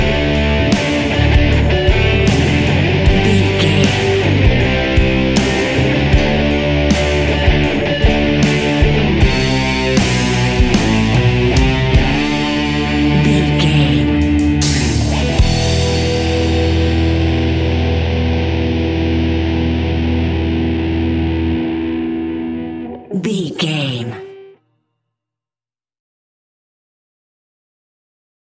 Epic / Action
Aeolian/Minor
hard rock
blues rock
instrumentals
rock guitars
Rock Bass
Rock Drums
heavy drums
distorted guitars
hammond organ